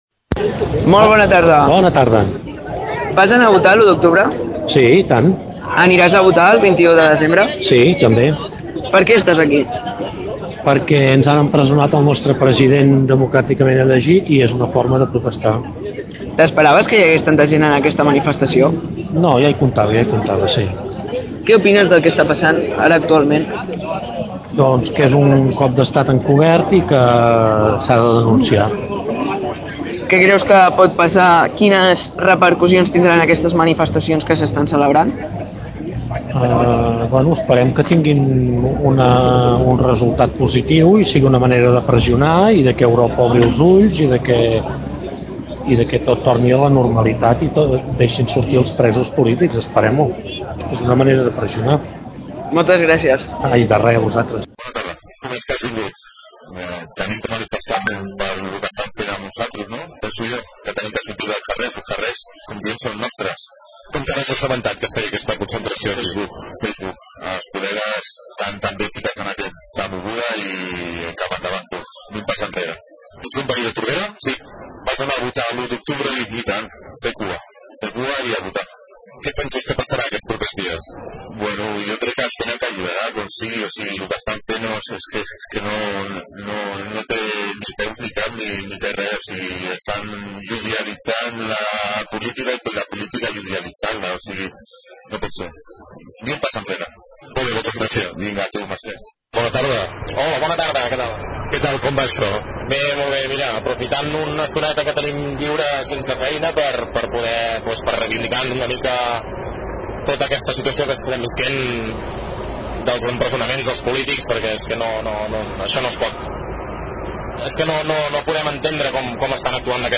Recollíem algunes impressions dels assistents sobre la situació del país.
opinions-concentracio-3N.mp3